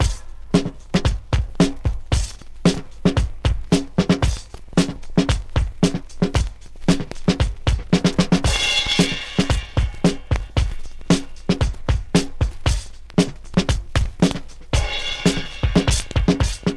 • 114 Bpm Modern Drum Groove F Key.wav
Free breakbeat - kick tuned to the F note. Loudest frequency: 1452Hz
114-bpm-modern-drum-groove-f-key-Xcu.wav